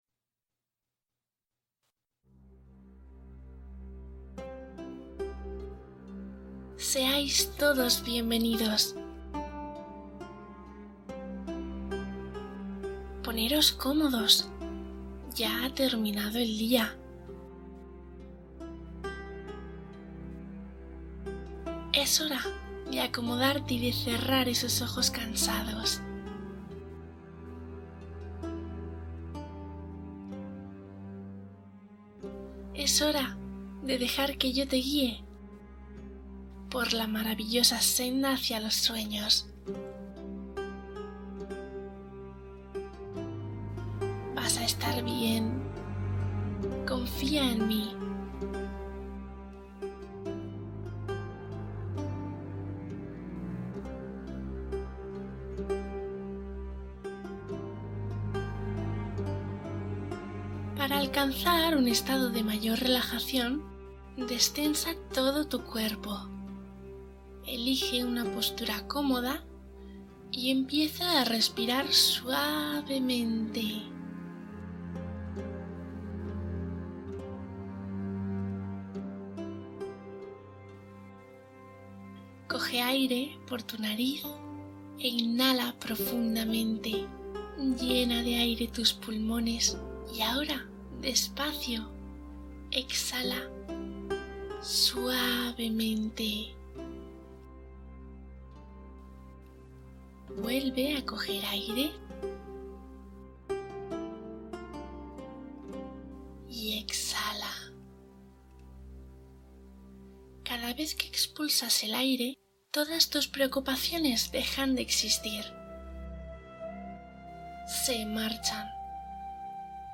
Viaje a la India | Meditación guiada para dormir profundamente